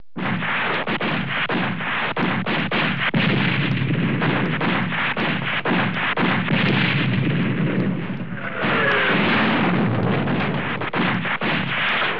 gunbattle1.wav